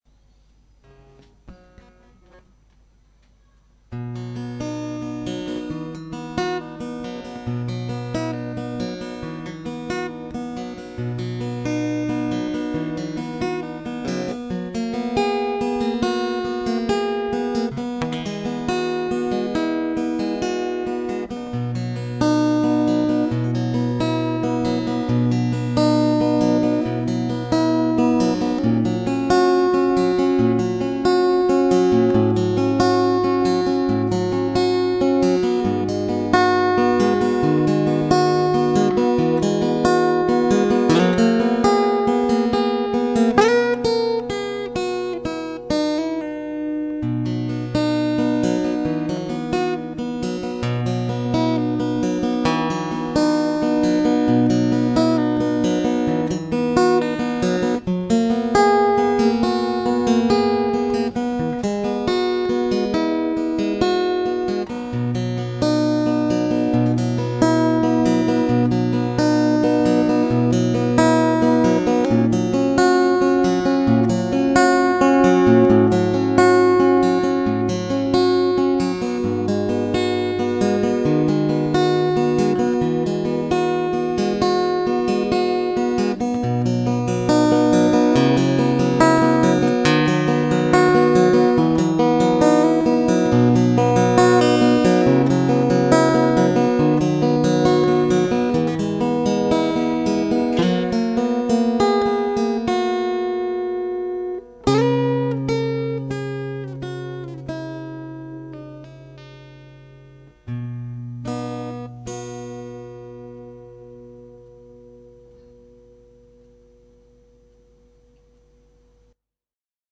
esto, mal ejecutado porque estaba nervioso como un niño.